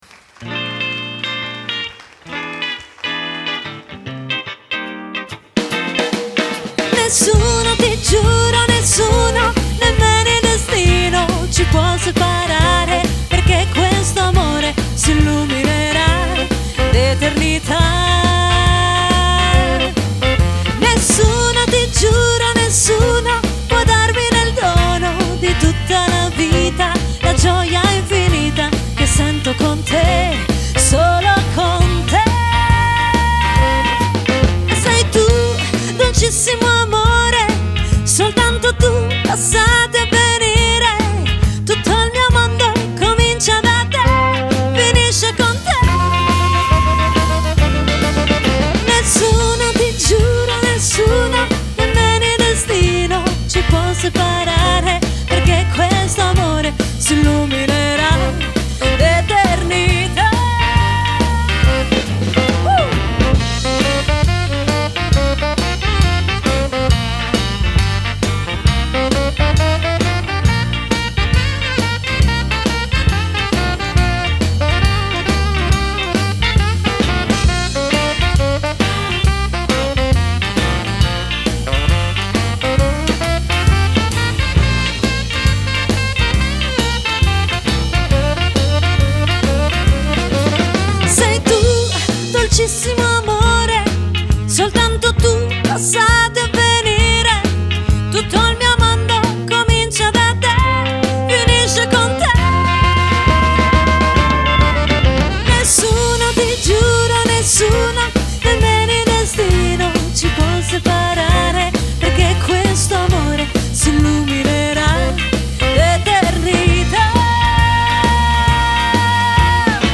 dal twist al beat a tanto altro ancora.
LIVE